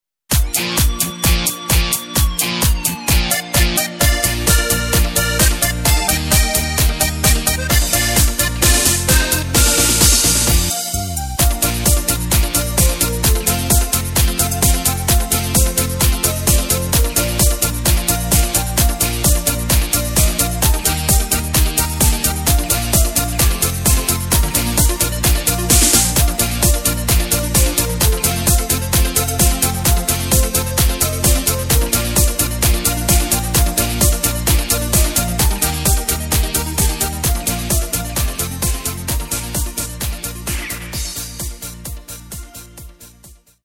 Takt:          4/4
Tempo:         130.00
Tonart:            Bb
Faschings Hit aus dem Jahr 2014!
Playback mp3 Demo